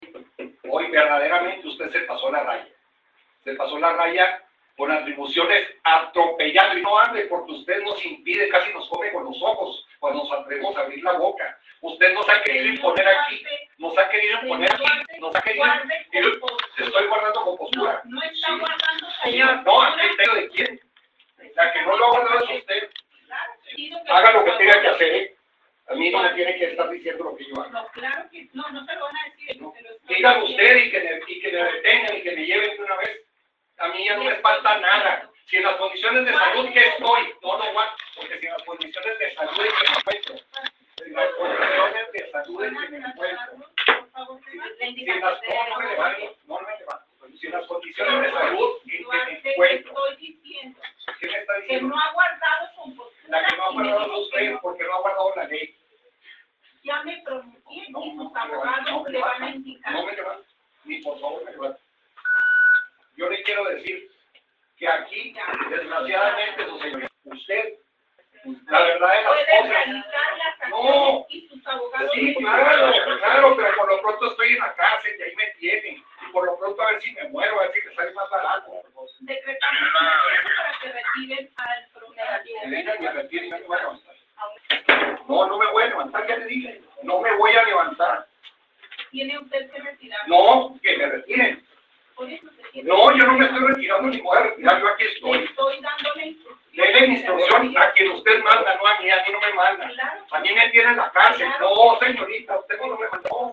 Antes del desalojo, se dio un intercambio de entre la juez y el detenido, debido a que varias ocasiones la imprimadora de justicia pidió al imputado guarde compostura y este perdía el control y levantaba la voz, lanzado una serie de acusaciones sobre la actuación de la juez.